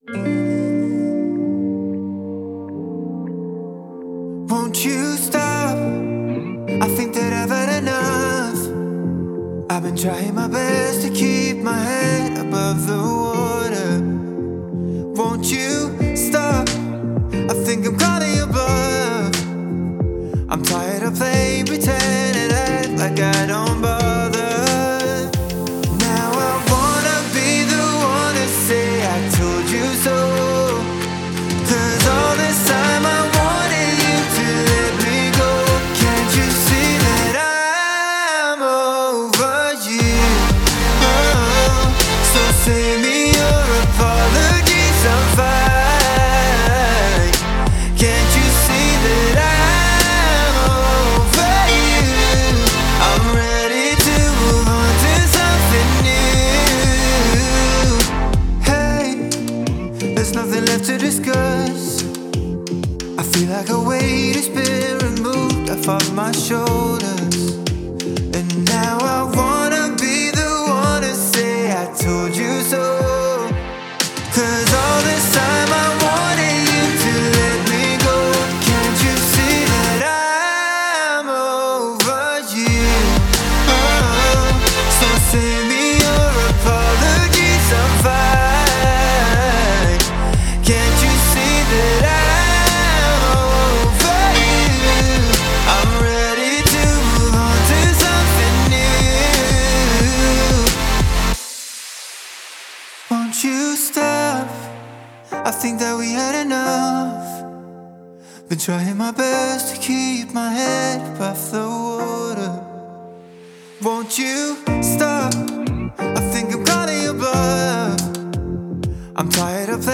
это эмоциональная поп-рок композиция
пронизанная чувственными мелодиями и мощными вокалами.